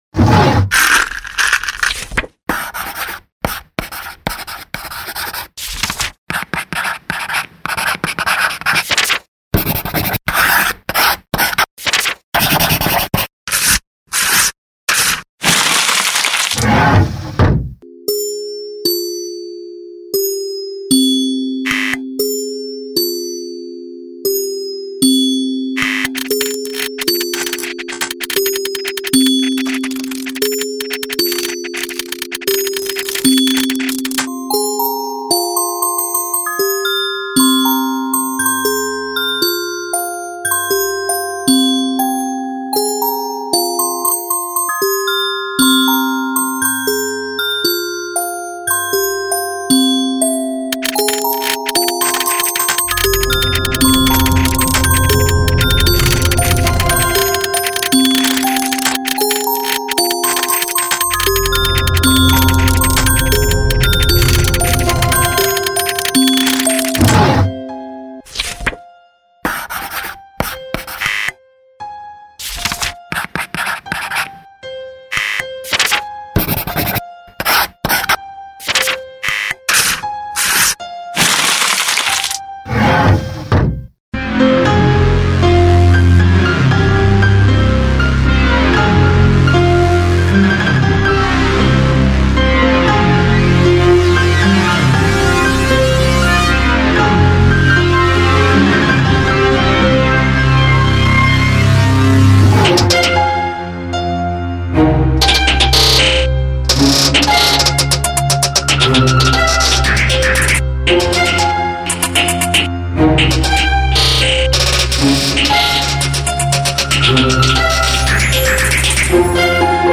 Работа в стиле Intelligent Dance Music.